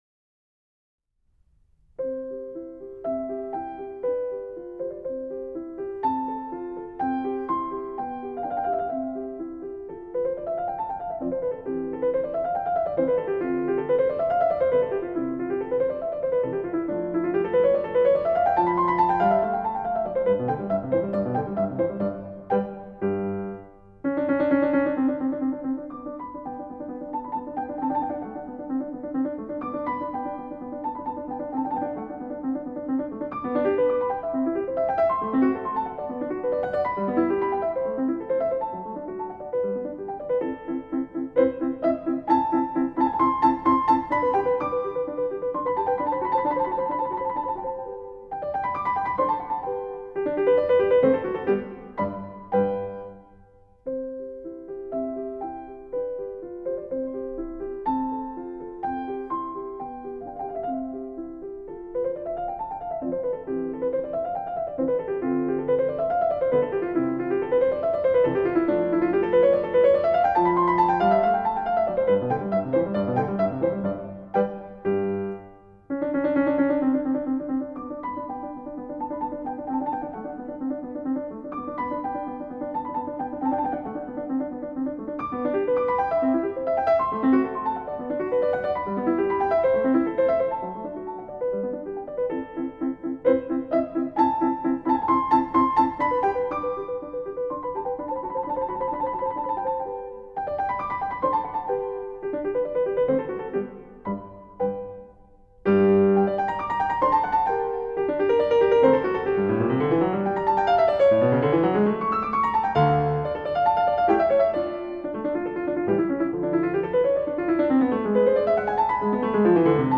Wolfgang Amadeus Mozart, Piano Sonata in C Major, K.545, I. Allegro (exposition only) (1788)
mozart-sonata-545-i-exposition.mp3